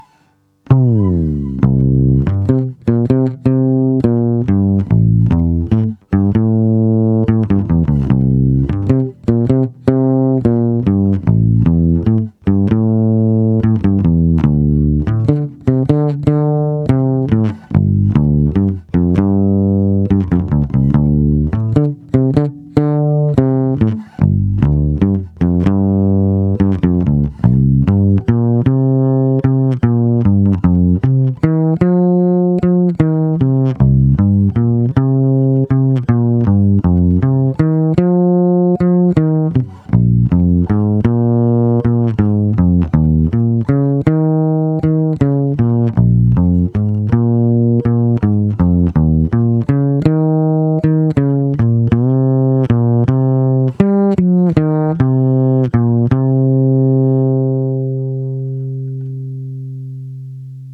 Zvuk je opravdu hutný a i díky piezo snímači dostává akustický, až kontrabasový nádech. Ostatně posuďte sami z nahrávek, které jsou pořízené přes zvukovku do PC, bez úprav.
Máš moc velkou citlivost na vstupu, takže je signál ořezaný, zkreslený a proto to ve zvuku chrčí.